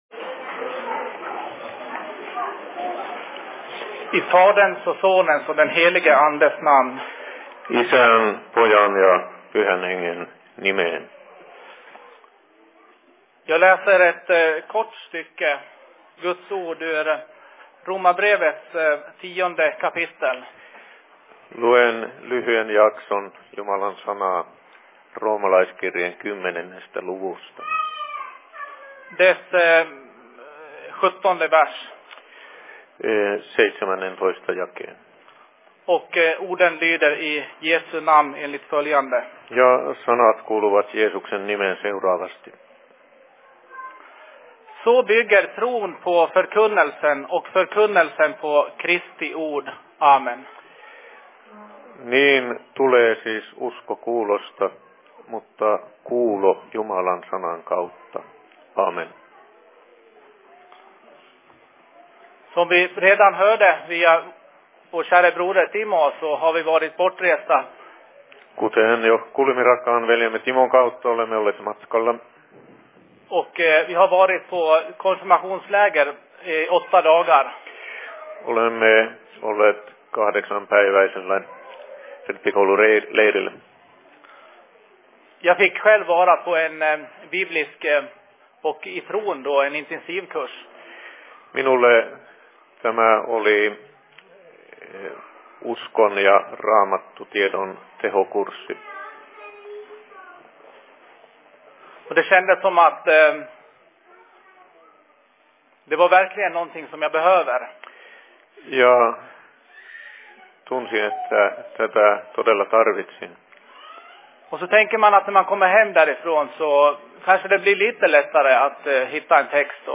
Se Fi Predikan I Dalarnas Fridsförening 15.08.2010
Paikka: SFC Dalarna
2010 Simultaanitulkattu Ruotsi